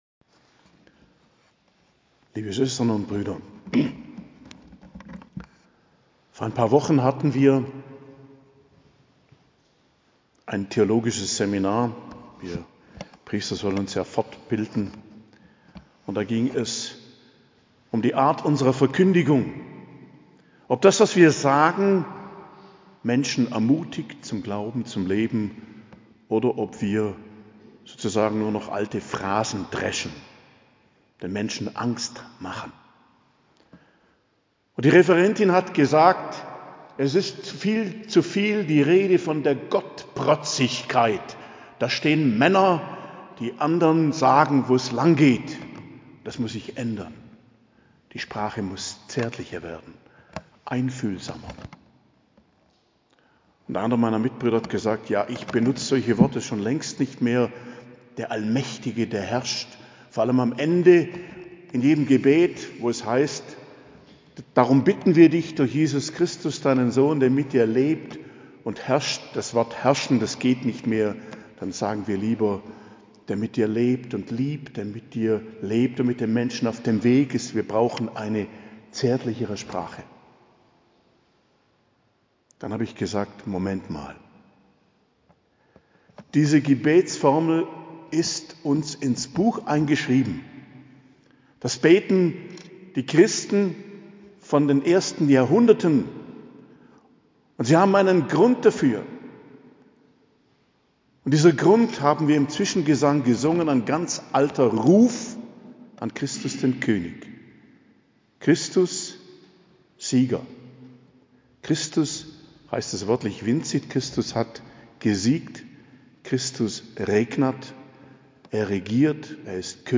Predigt zum Christkönigssonntag, 26.11.2023 ~ Geistliches Zentrum Kloster Heiligkreuztal Podcast